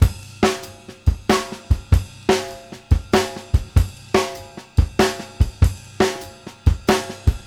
CYM GROOVE-R.wav